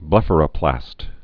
(blĕfər-ə-plăst)